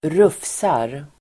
Ladda ner uttalet
Uttal: [²r'uf:sar]
rufsar.mp3